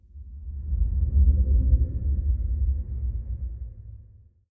Ambient6.ogg